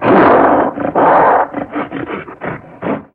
izlome_attack_9.ogg